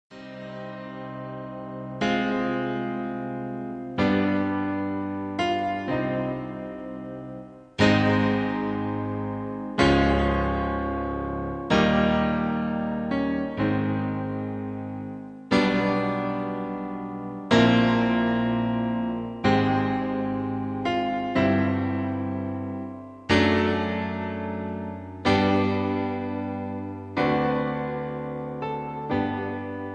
easy litstening, rock and roll